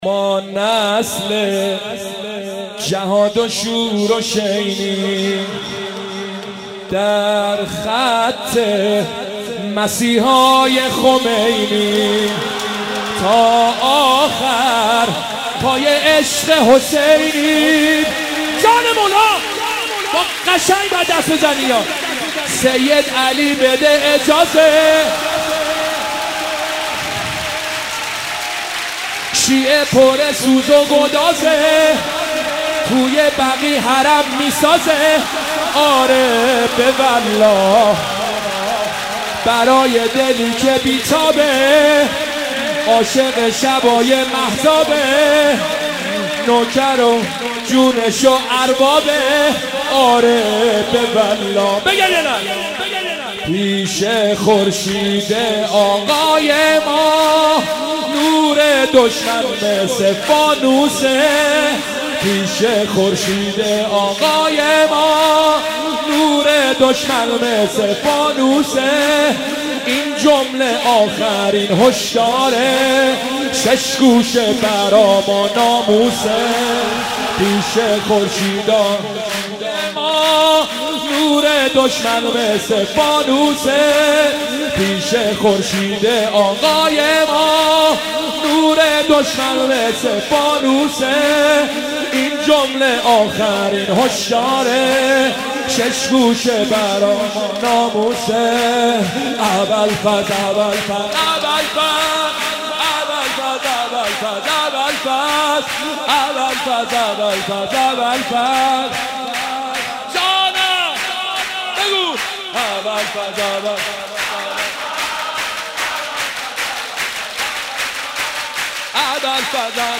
(سرود)
مولودی